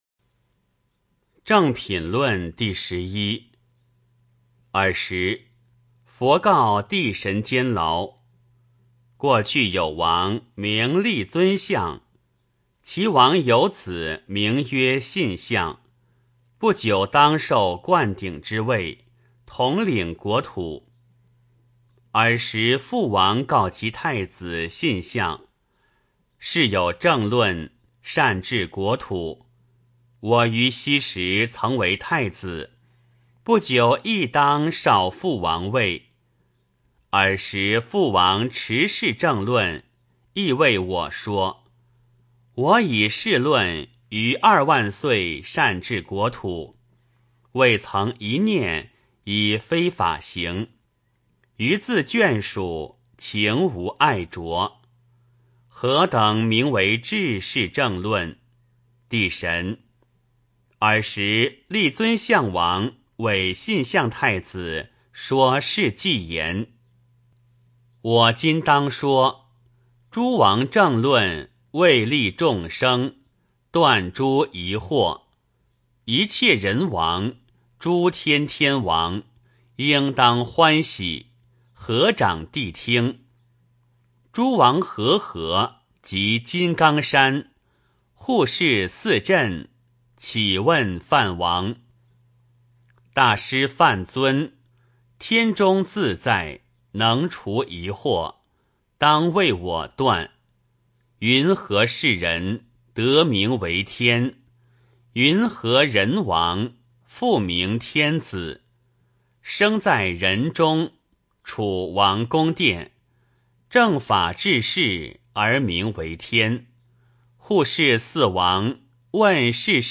金光明经-正品论第十一 诵经 金光明经-正品论第十一--未知 点我： 标签: 佛音 诵经 佛教音乐 返回列表 上一篇： 金光明经.大辩天神品第七 下一篇： 金光明经-鬼神品第十三 相关文章 浴佛颂--寺院 浴佛颂--寺院...